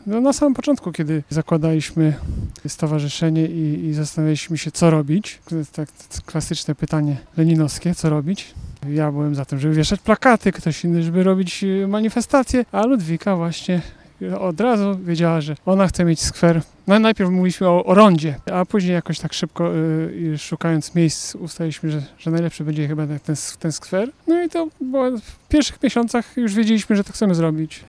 W piątek (14.09.18) w pobliżu Urzędu Miejskiego odbyła się uroczystość odsłonięcia skweru imienia byłego premiera.